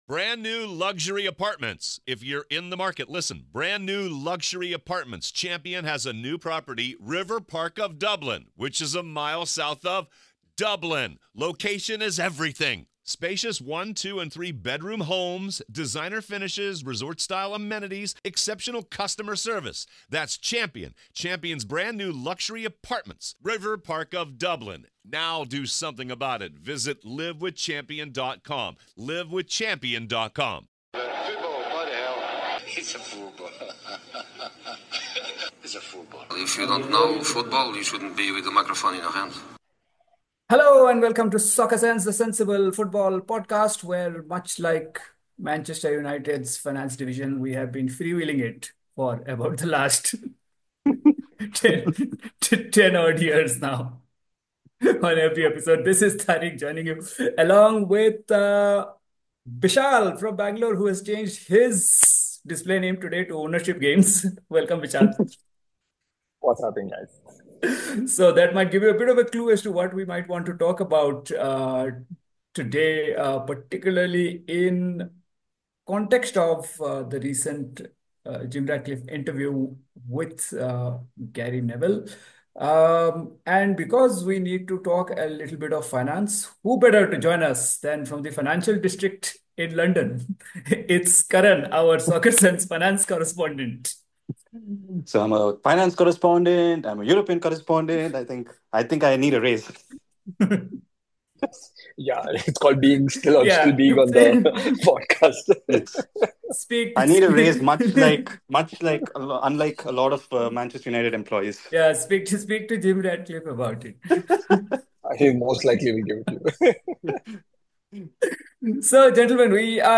Headliner Embed Embed code See more options Share Facebook X Subscribe This week, the panel - none of whom are even capable of filing their own taxes - pore into the financials of Manchester United and discuss the dilemma of how a big football club can balance commerce, community and conscience while dealing with an existential crisis, and figure out if there are indeed sensible ways to think about their current position.